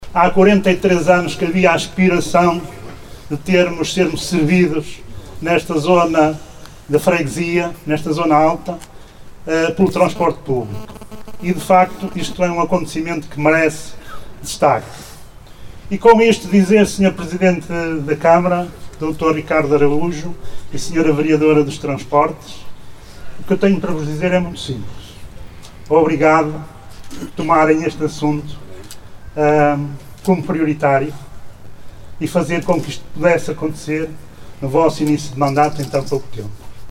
O presidente da Junta de Creixomil, António Gonçalves, destaca a importância desta linha para a comunidade de algumas zonas da freguesia que desde há décadas reclamava uma ligação regular.